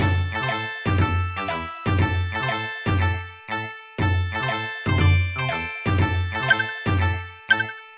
The background song
Source Recorded with an AUX cable from my 3DS to my PC.